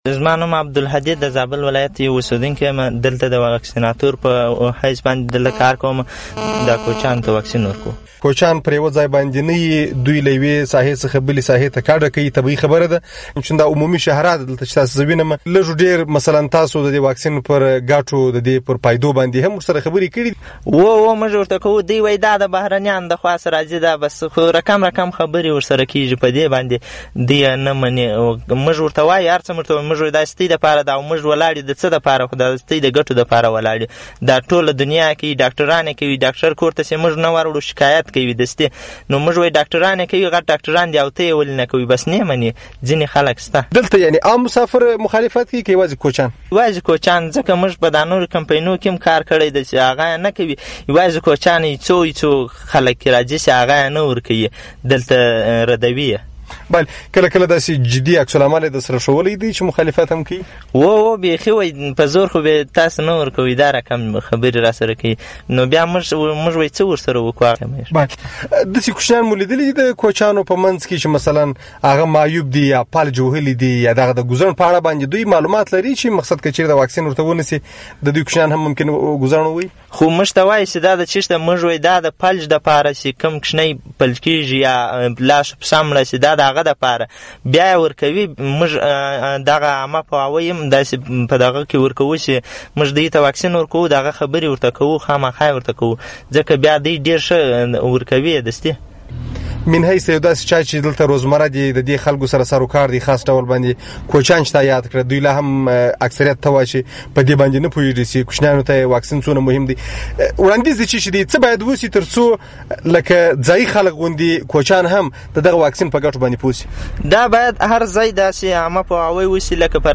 بشپړه مرکه